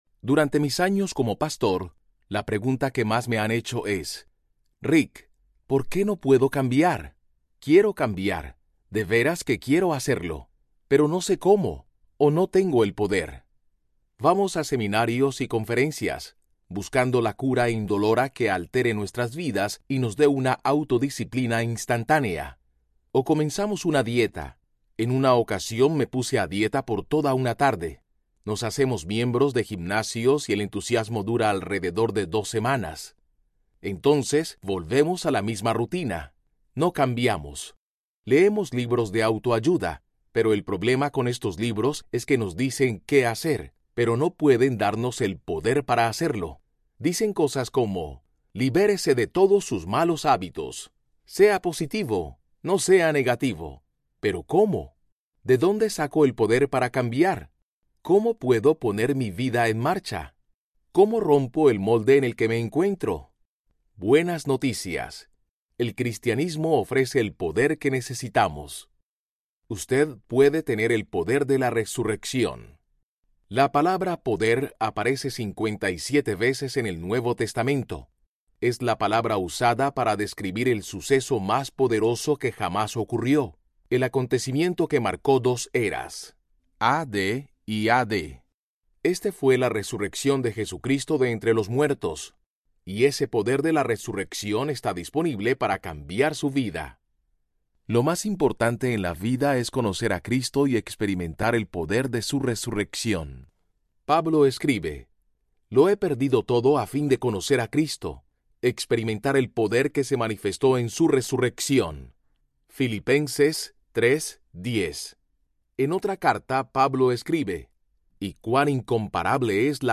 El poder de Dios para transformar su vida Audiobook
Narrator
5.5 Hrs. – Unabridged